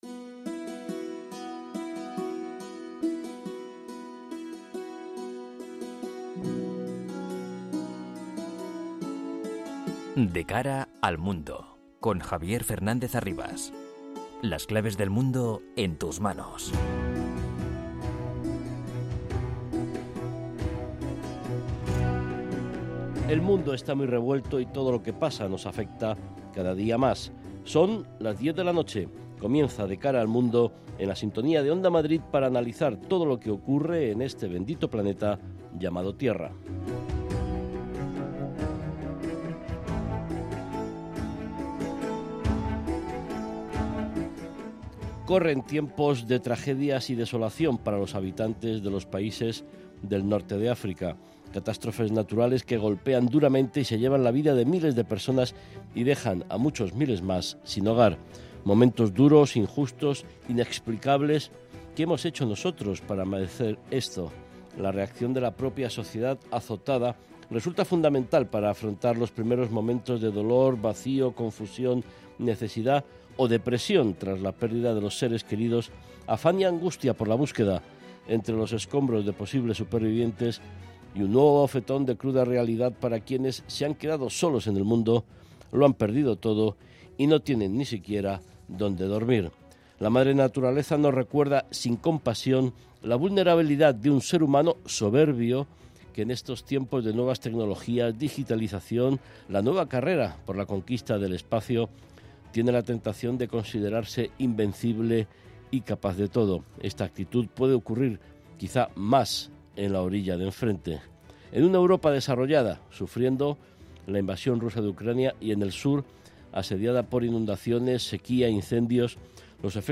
con entrevistas a expertos y un panel completo de analistas